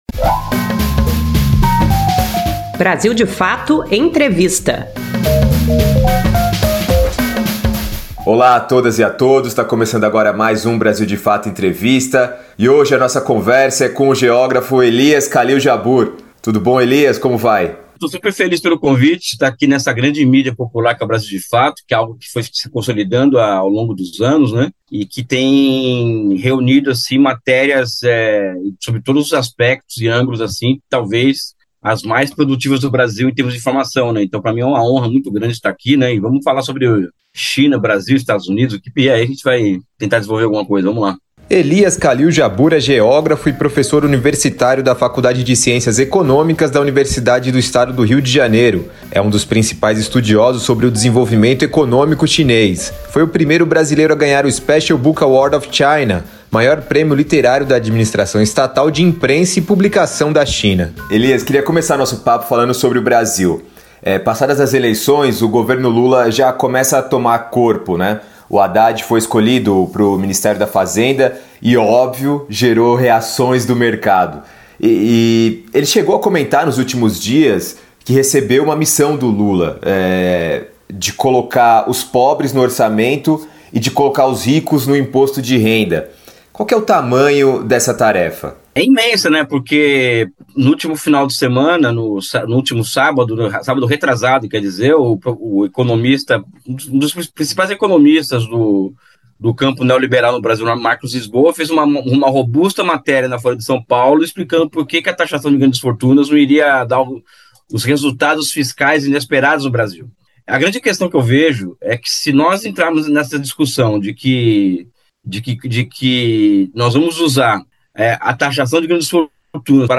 BdF Entrevista